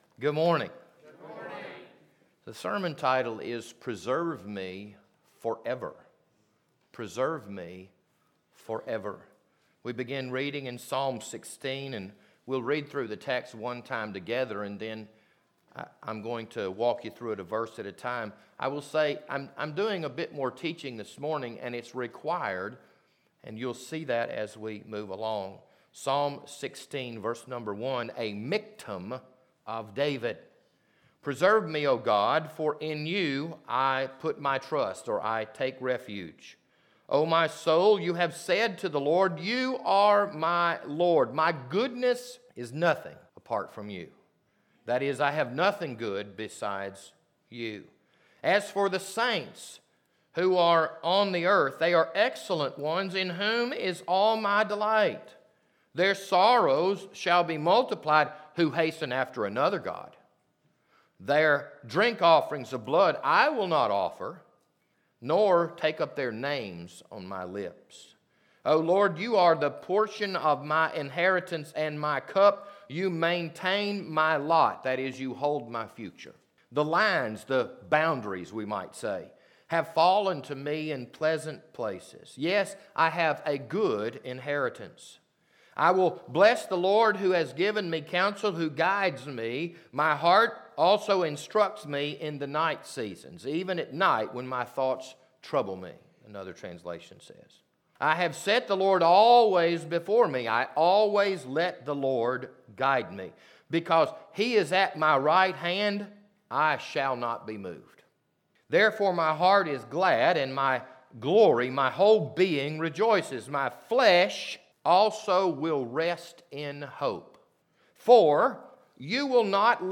This Sunday morning sermon was recorded on March 20th, 2022.